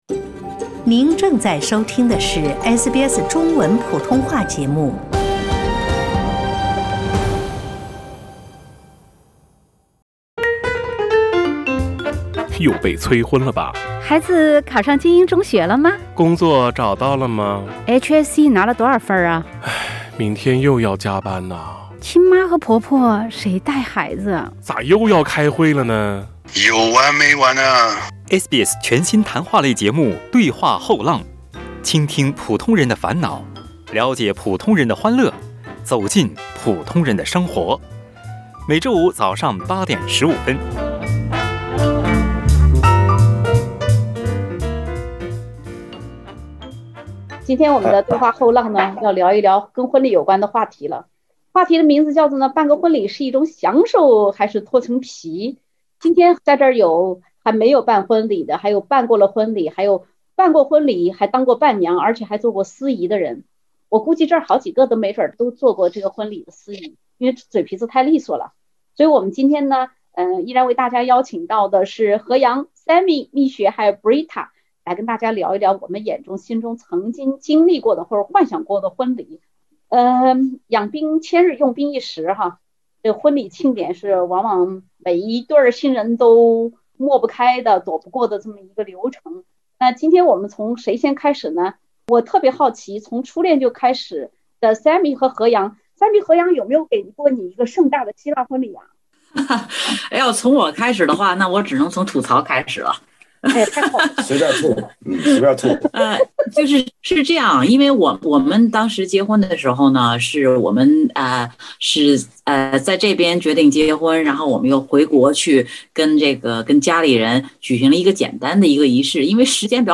欢迎收听澳大利亚最亲民的中文聊天类节目-《对话后浪》。